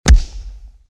human
Mega Body Punch 4